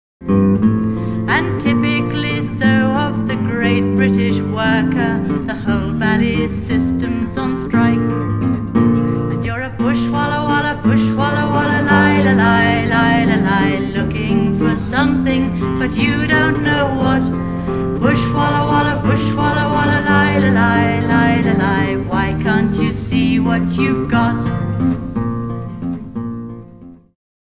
Samples are lower quality for speed.